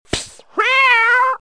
Cat Hit Efecto de Sonido Descargar
Cat Hit Botón de Sonido